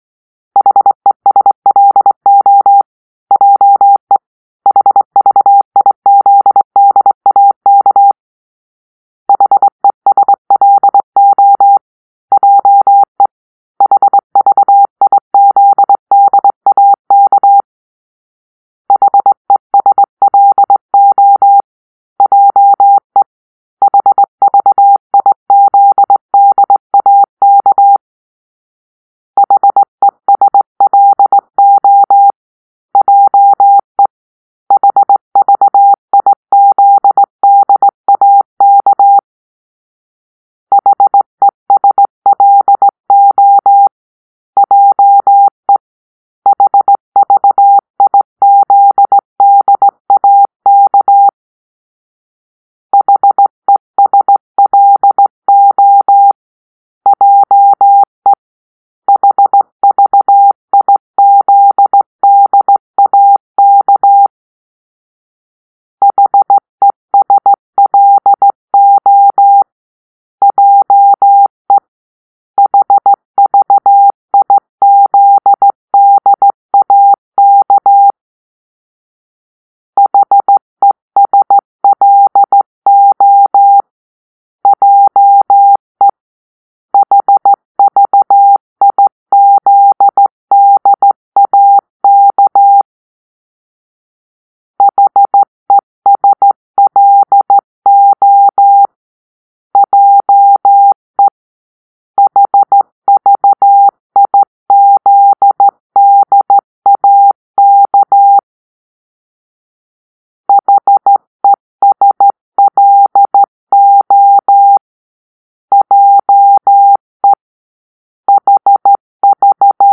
1-morseovka